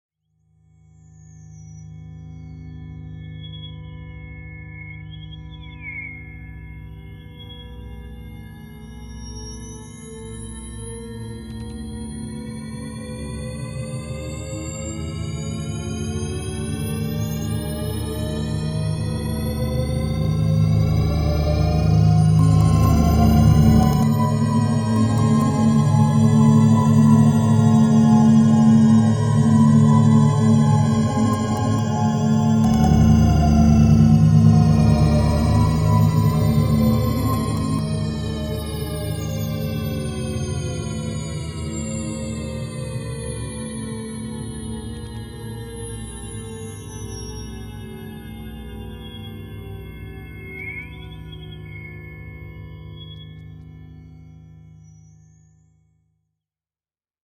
takeoff_sound.mp3